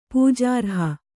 ♪ pūjārha